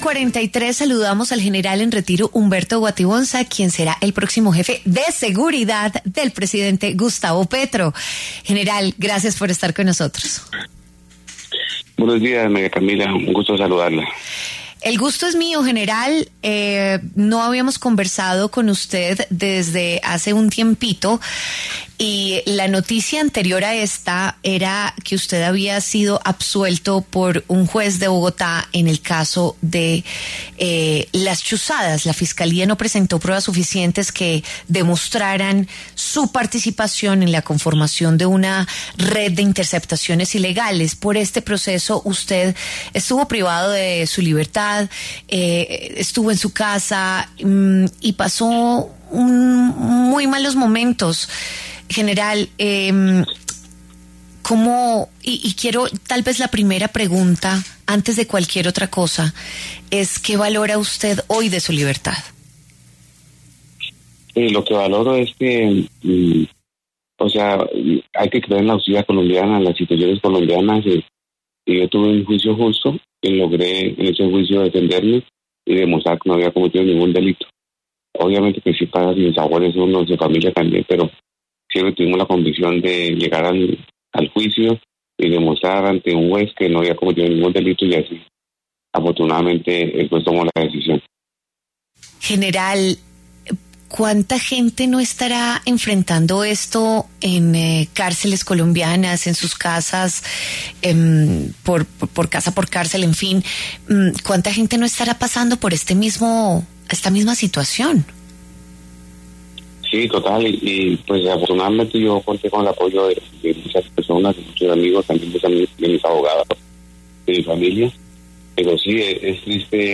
El general en retiro Humberto Guatibonza habló en W Fin de Semana a propósito de su designación como jefe de seguridad del presidente Gustavo Petro.